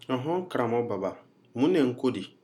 Dialogue: [nko]ߒߞߏ ߝߐߟߊ ߎ߬[/nko]